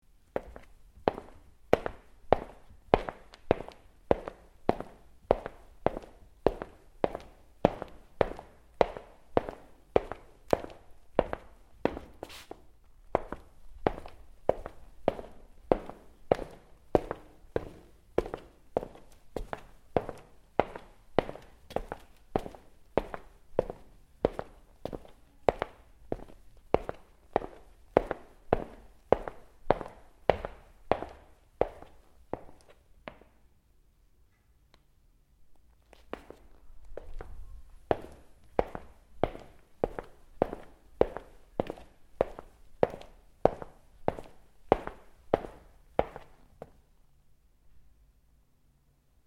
Шаги солдата-щелкунчика по паркету